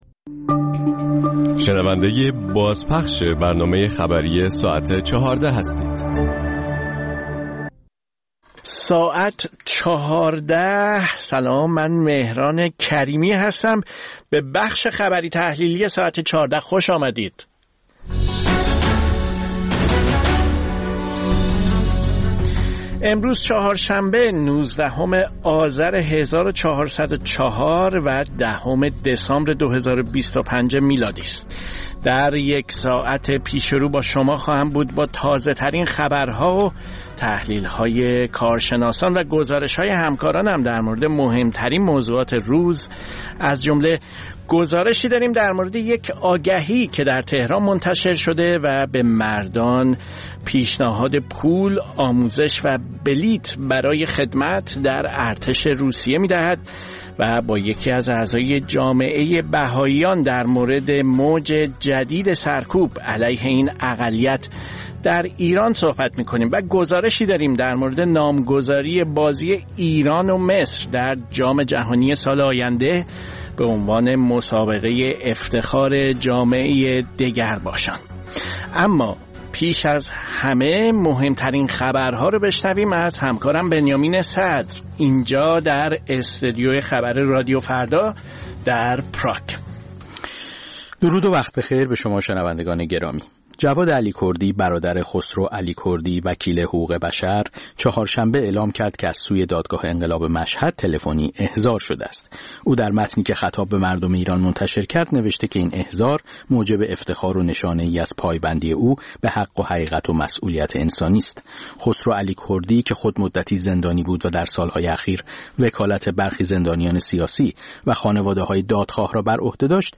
مجموعه‌ای از اخبار، گزارش‌ها و گفت‌وگوها در ساعت ۱۴